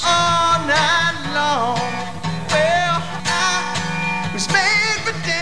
Sound bytes were captured from the vh-1 Special of Leif Garrett:  Behind The  Music and Where Are They Now.